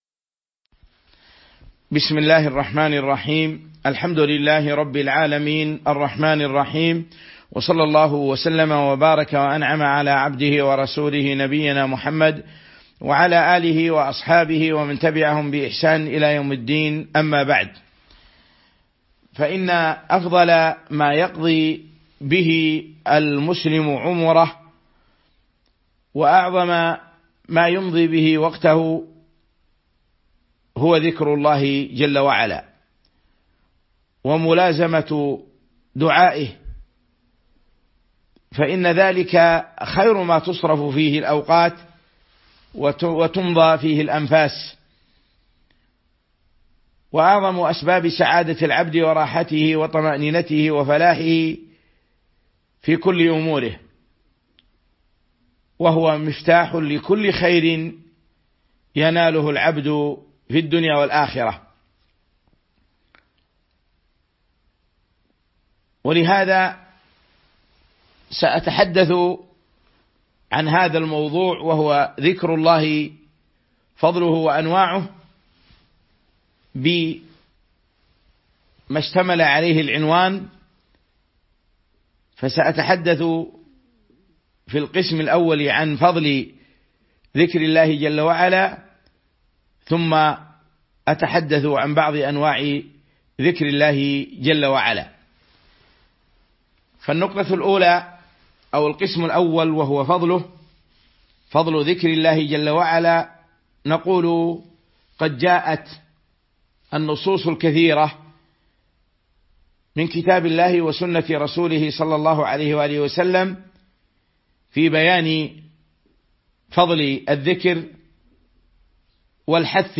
تاريخ النشر ١٦ جمادى الأولى ١٤٤٢ هـ المكان: المسجد النبوي الشيخ